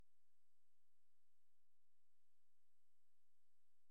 laid-back | groovy | reggae